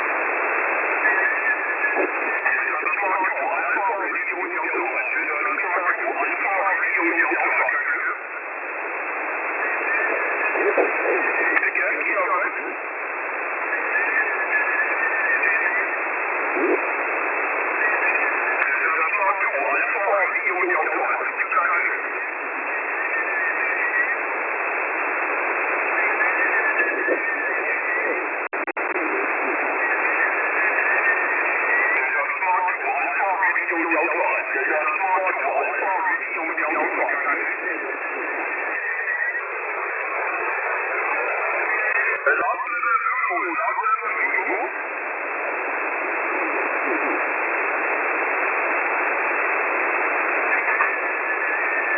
WEB-SDR Aufnahmen von verschieden Standorten